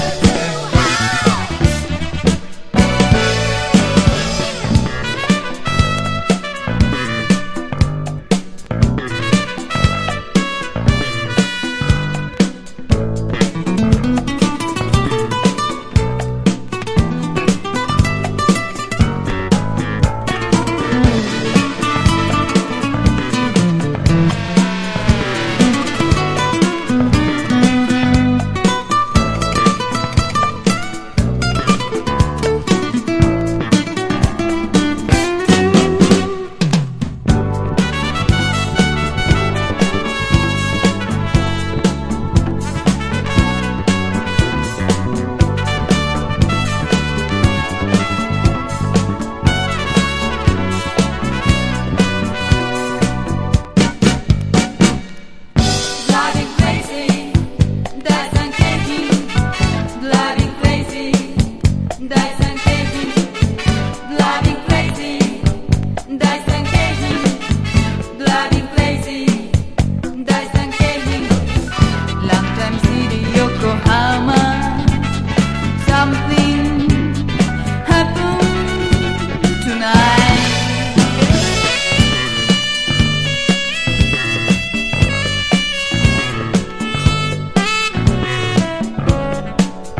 FREE SOUL# CITY POP / AOR